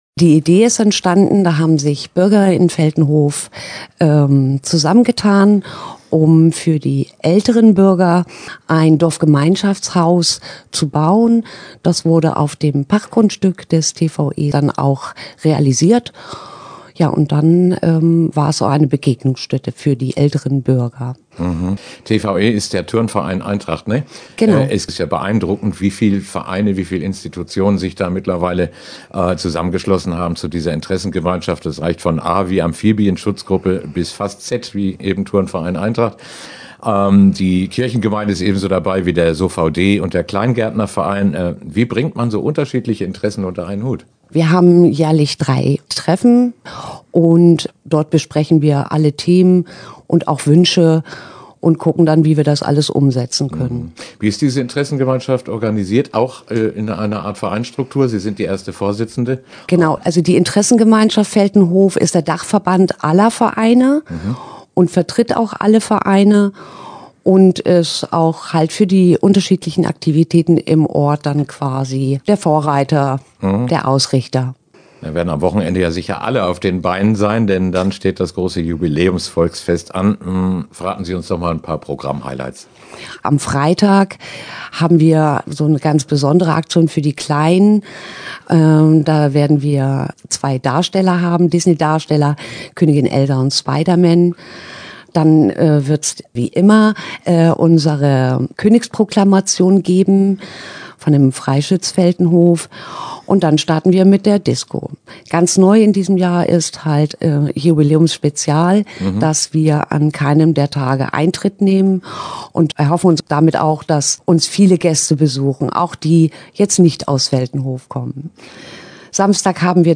Interview-Volksfest-Veltenhof_pmm.mp3